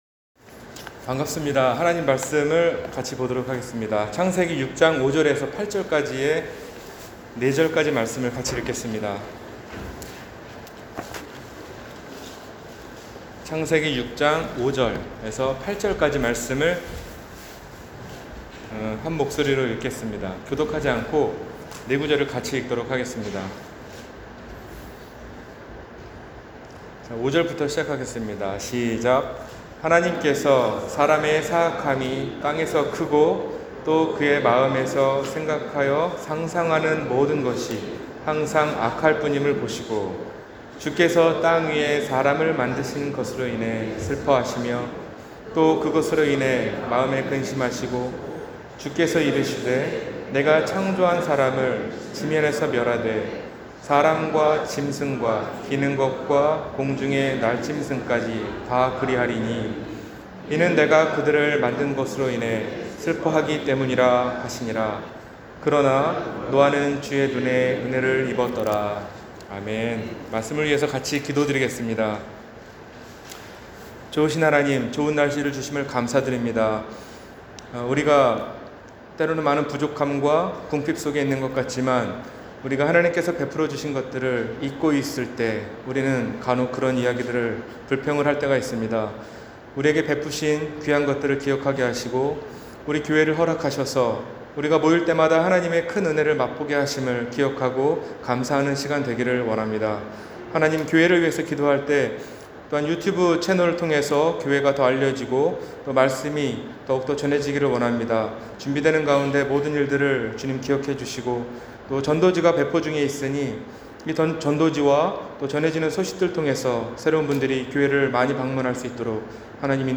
심판과 믿음 – 주일설교